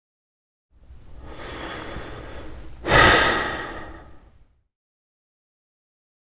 دانلود صدای نفس عمیق مرد 1 از ساعد نیوز با لینک مستقیم و کیفیت بالا
جلوه های صوتی